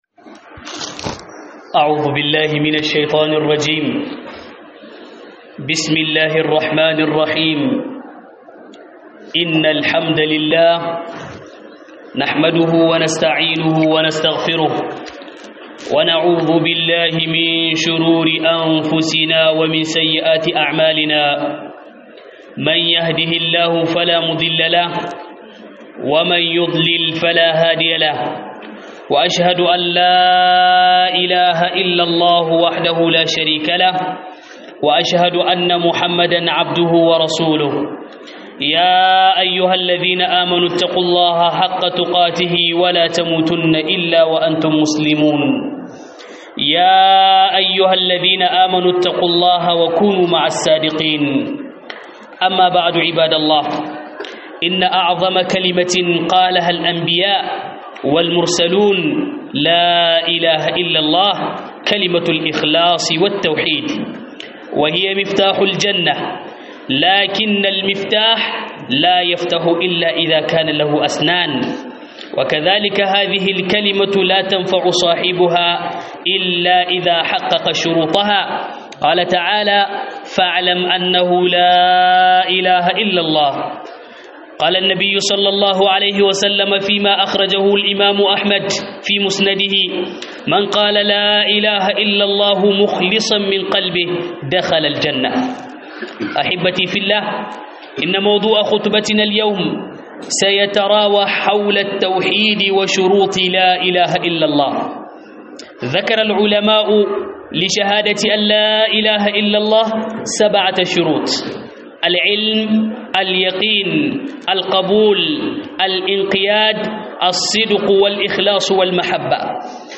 Huduba akan Tauhidi - HUDUBA